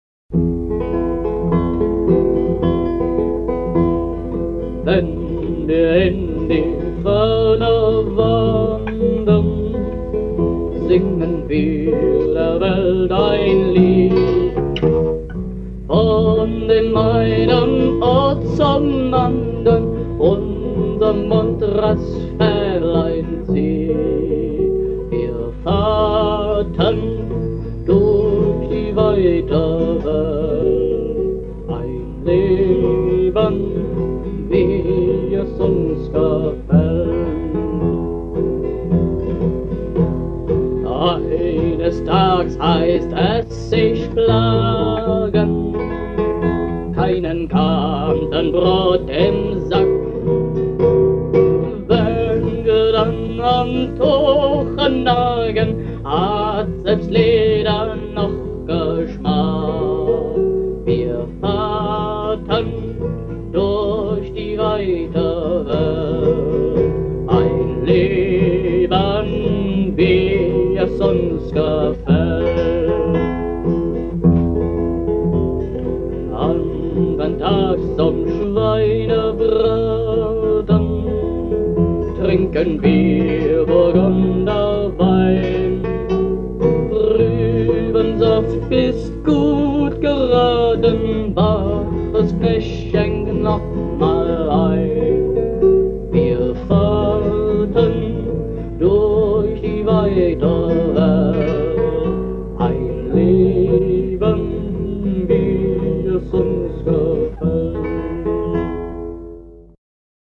Frühe Fassung von 1977 (Version 2 - getragen)